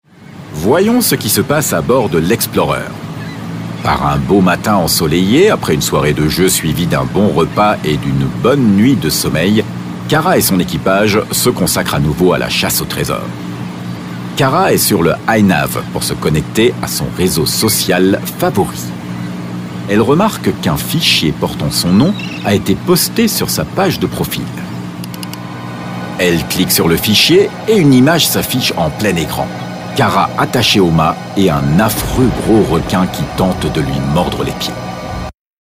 Dank seiner angenehmen Tonlage, nicht zu tief und auch nicht zu hell, ist er sehr flexibel einsetzbar.
Kommentar